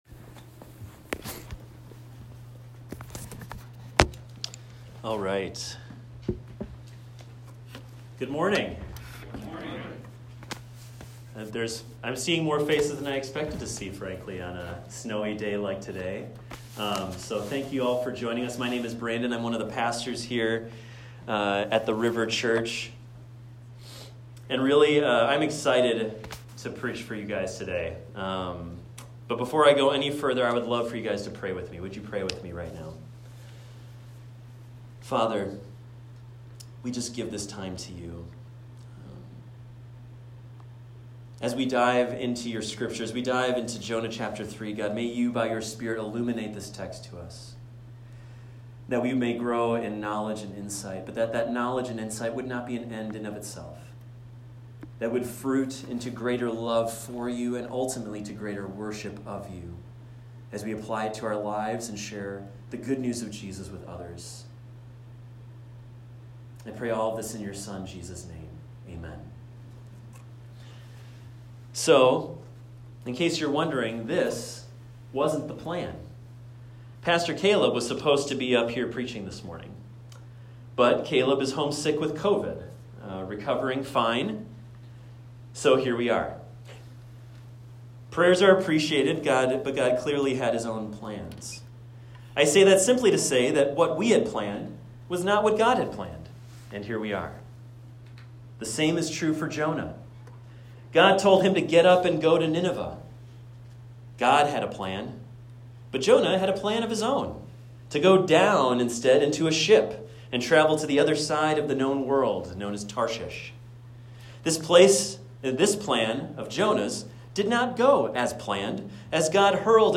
This is a recording of a sermon titled, "Chapter 3."